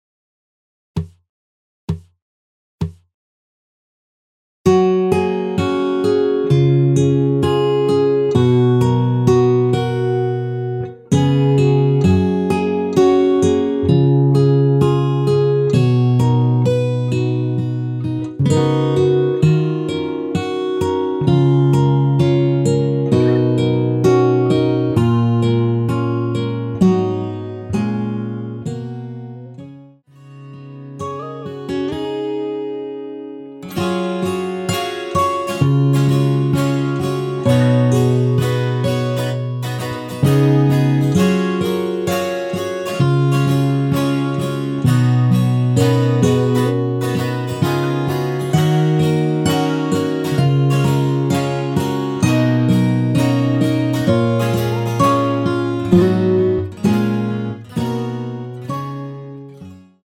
전주없이 시작 하는 곡이라 카운트 넣어 놓았습니다.(미리듣기 참조)
F#
◈ 곡명 옆 (-1)은 반음 내림, (+1)은 반음 올림 입니다.
앞부분30초, 뒷부분30초씩 편집해서 올려 드리고 있습니다.